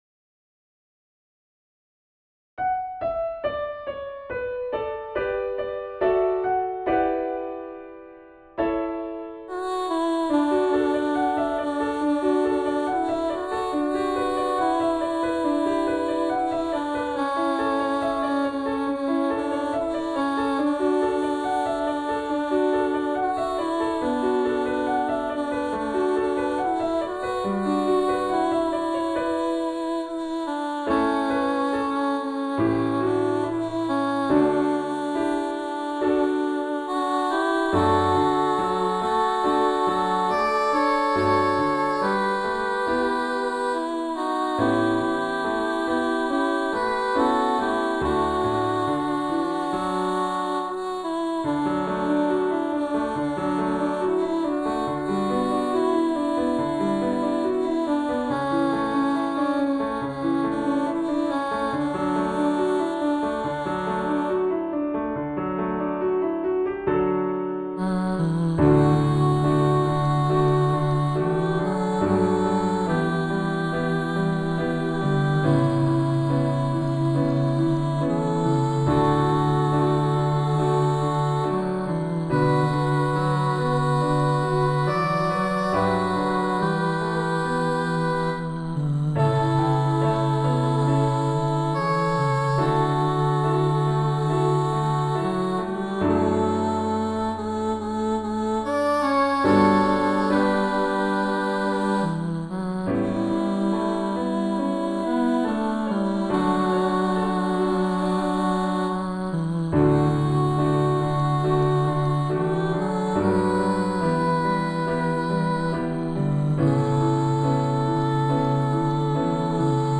Voicing/Instrumentation: SATB , SATB quartet